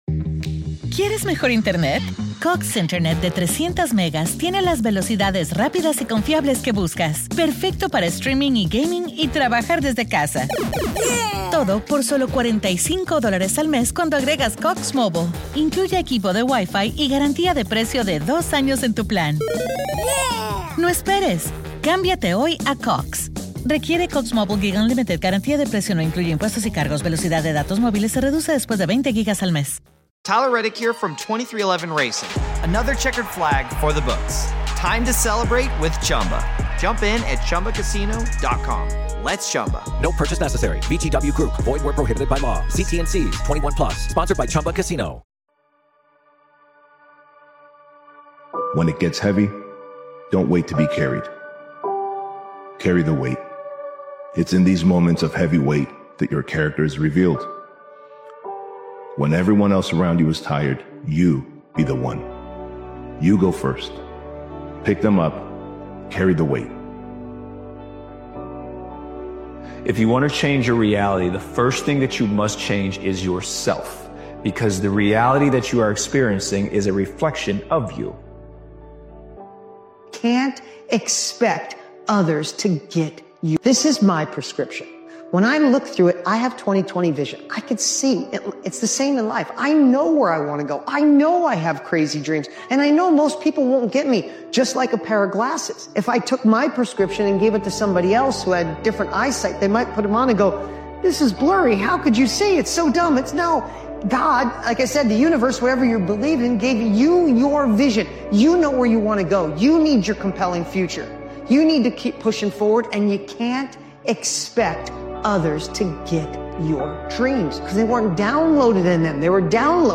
This strong motivational speeches compilation reminds you that nothing around you improves until something within you evolves.